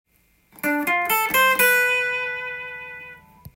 エレキギターで弾ける【Gミクソリディアンフレーズ集】オリジナルtab譜つくってみました
⑤のフレーズは、Gのミクソディアンスケール内ですが
Dmのコードを意識したフレーズになっています。
変な風には聞こえません。最後はG7の３度の音で着地です。